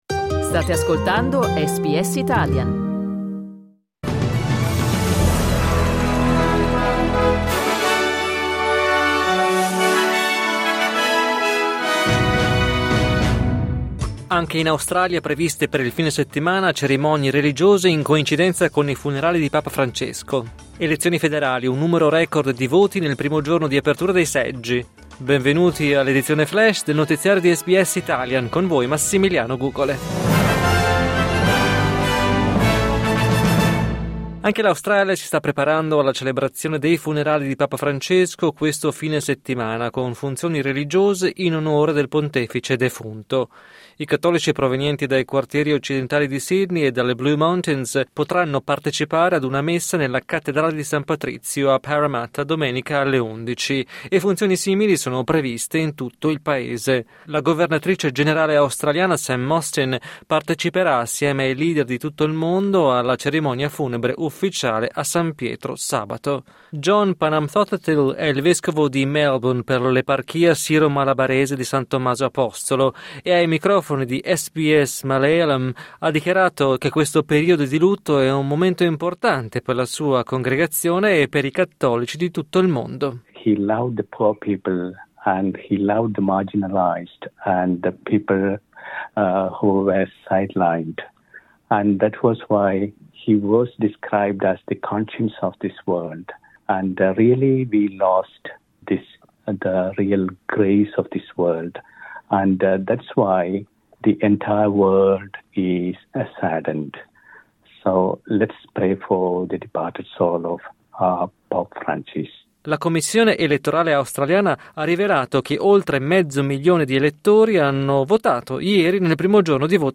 News flash mercoledì 23 aprile 2025